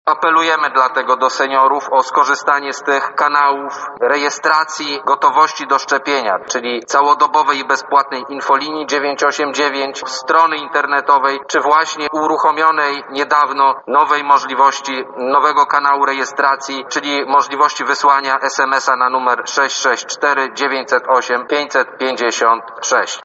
System, który został stworzony pozwoliłby na zaszczepienie 11 mln – wyjaśnia szef Kancelarii Premiera Michał Dworczyk: